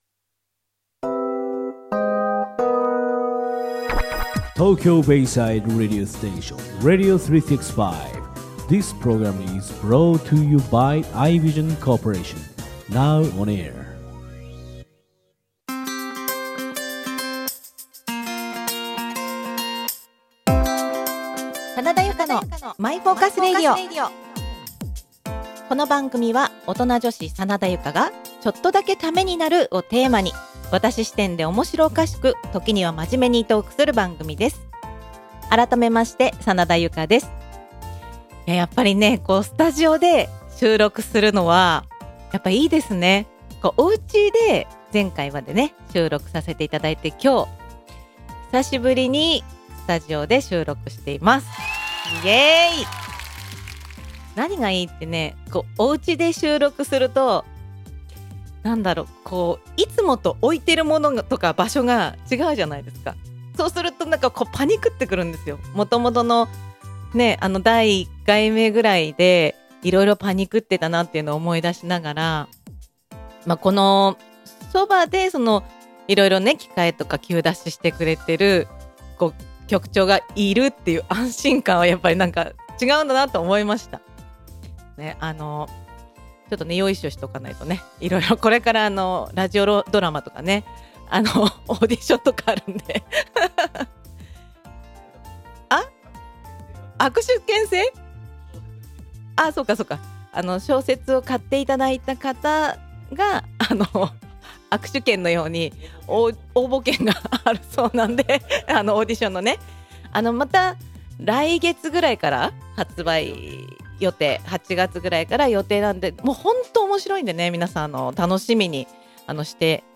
久しぶりのスタジオ収録！！やっぱりお台場の景色見ながらのラジオ収録はテンション上がりますね！